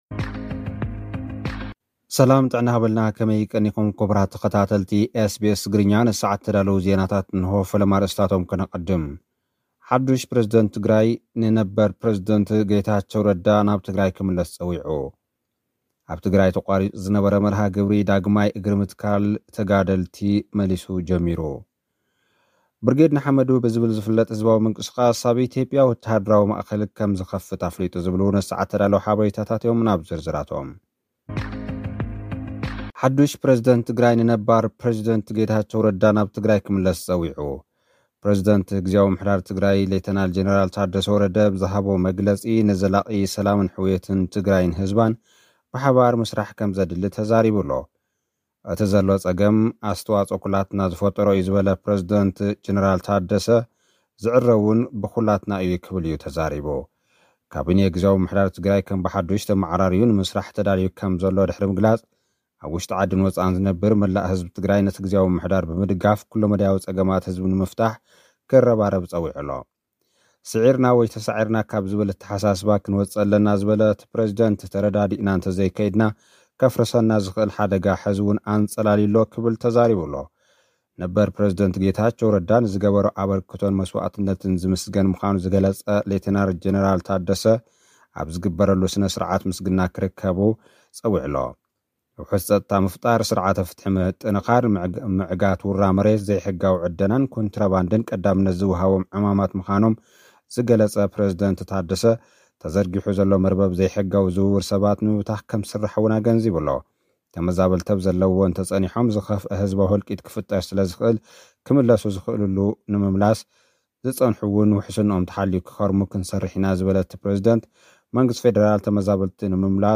ልኡኽና ዝሰደደልና ጸብጻብ፥ ሓዱሽ ፕረዚደንት ትግራይ ነበር ፕረዚደንት ጌታቸው ረዳ ናብ ትግራይ ክምለስ ጸዊዑ። ኣብ ትግራይ ተቛሪፁ ዝነበረ መርሃ ግብሪ ዳግማይ እግሪ ምትካል ነበር ተጋደልቲ መሊሱ ጀሚሩ። ብርጌድ ንሓመዱ ብዝብል ዝፍለጥ ህዝባዊ ምንቅስቓስ ኣብ ኢትዮጵያ ወተሃደራዊ ማእኸል ከም ዝኸፍት ኣፍሊጡ።